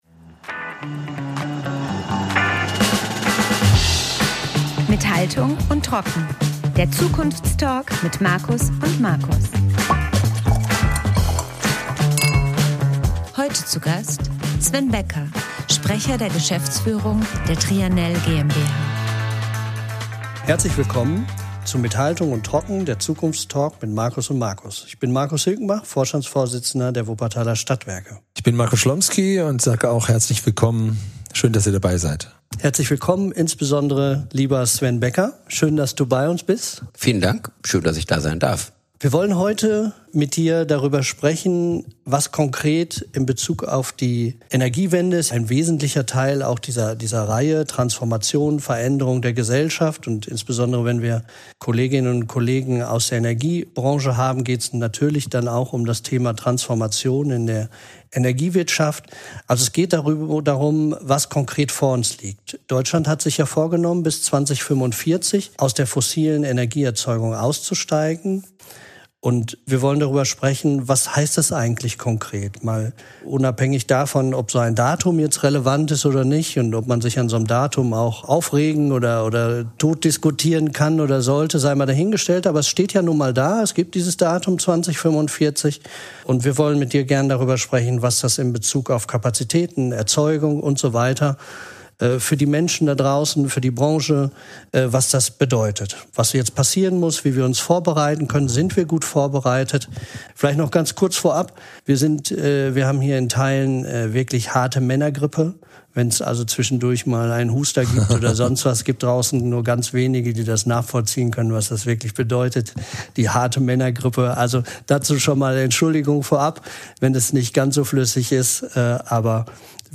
Ein Gespräch über Verantwortung, Akzeptanz und die Frage, wie Energiewende gelingen kann, ohne wirtschaftliche Substanz zu verlieren.